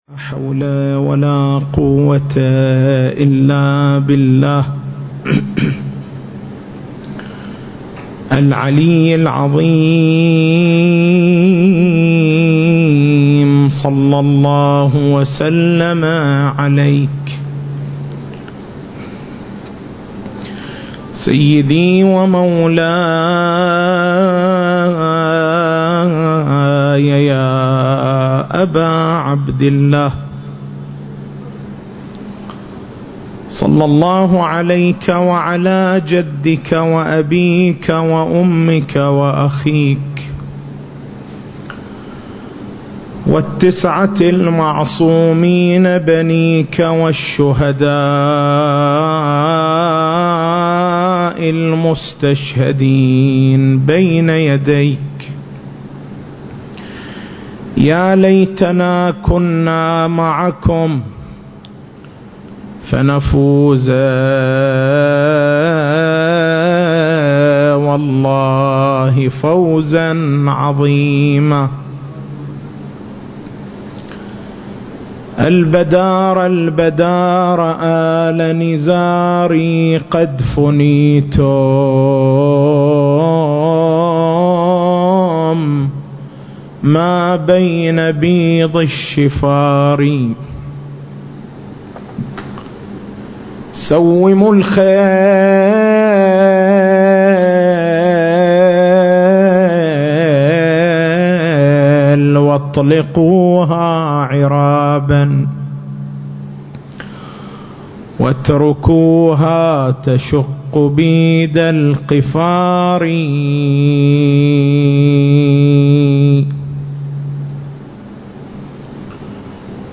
سلسلة: ولادة الإمام المهدي (عجّل الله فرجه) فوق التشكيك (5) نقاط البحث: - مقدمة في معنى التواتر وأقسامه - إثبات تواتر روايات ولادة الإمام المهدي (عجّل الله فرجه) المكان: حسينية الحاج حبيب العمران التاريخ: 1440 للهجرة